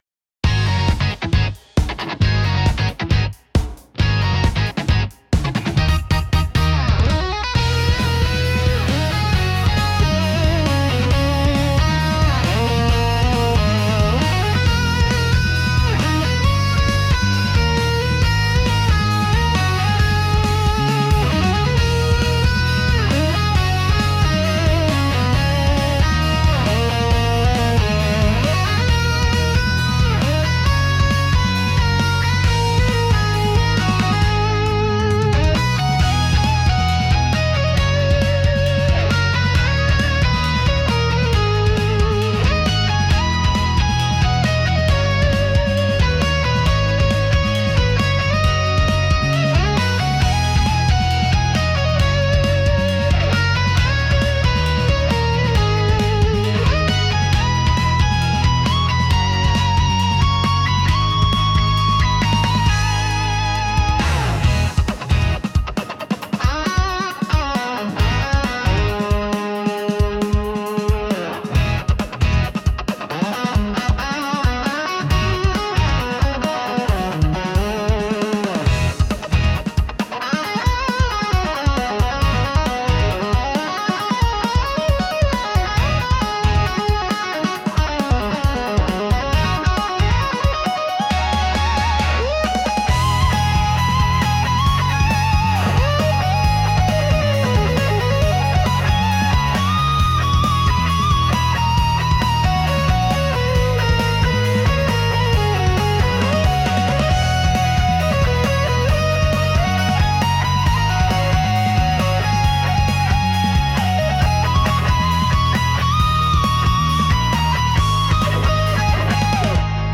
ふと不得意としている良い感じのファンタジー戦闘曲の序章っぽく形になったのでとても嬉しいです。